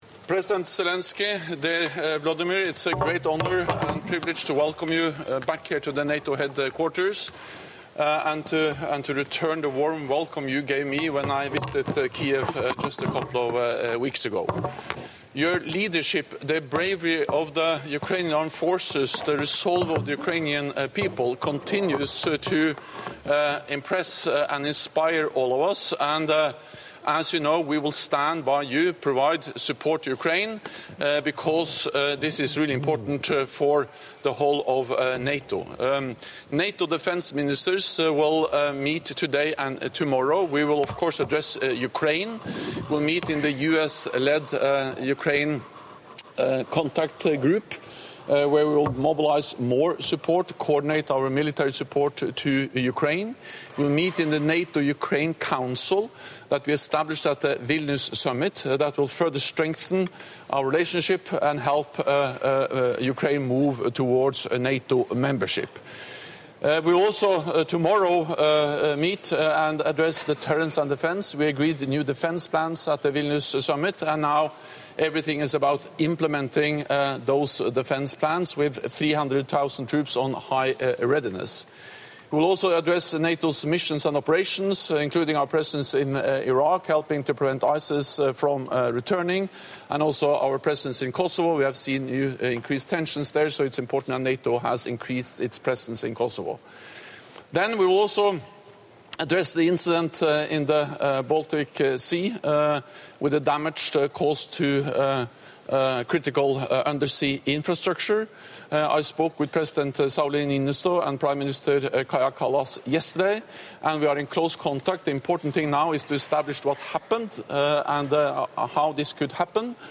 NATO Secretary General Jens Stoltenberg previewed the issues that NATO Defence Ministers will discuss over the next two days in Brussels, including support for Ukraine, strengthened deterrence and defence, NATO operations and missions, and the situation in the Middle East. He was joined by Ukrainian President Zelenskyy who is at NATO Headquarters to take part in the US-led Ukraine Defence Contact Group meeting, alongside more than 50 countries.